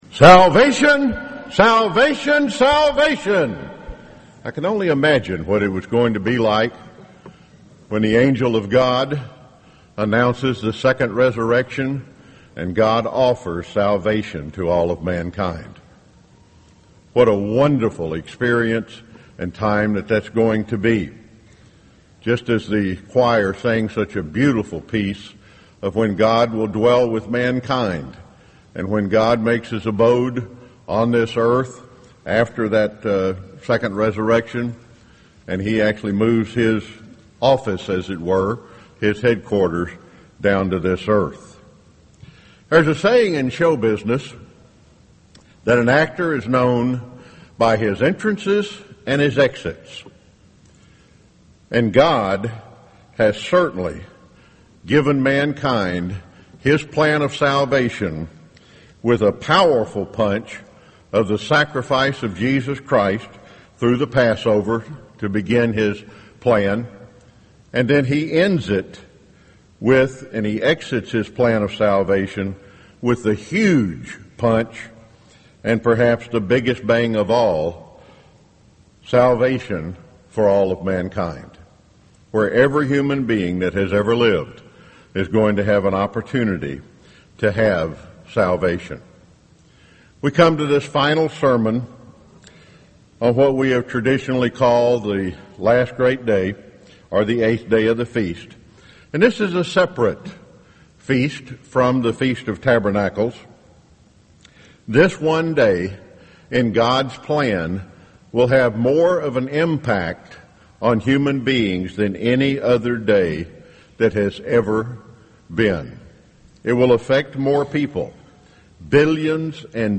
Sermons
Given in Jekyll Island, Georgia 2015